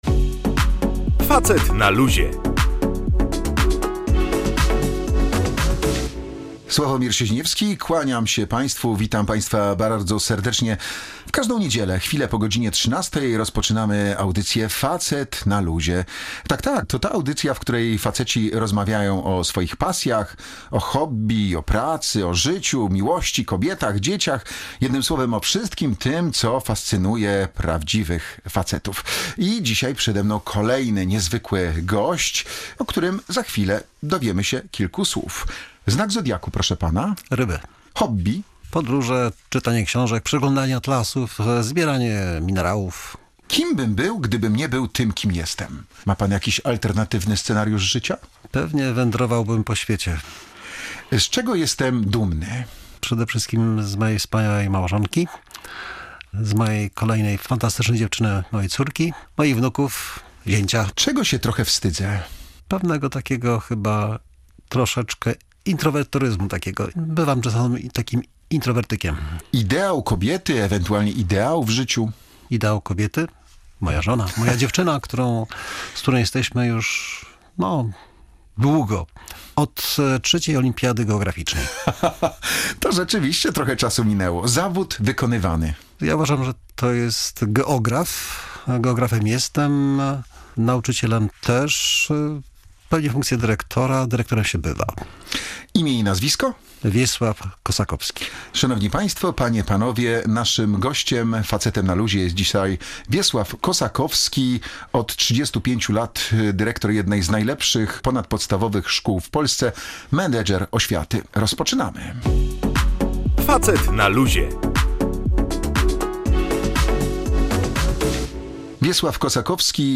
Analizowano także podstawy programowe, postawy nauczycieli oraz zachowania współczesnych uczniów. Była to audycja o współczesnej szkole, ale z uśmiechem.